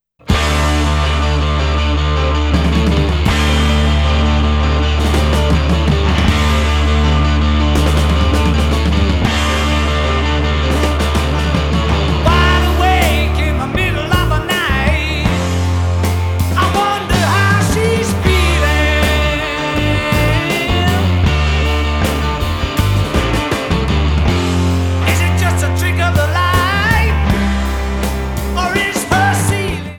1996 USA CD (Remix)   1996 Germany/UK CD (Remix)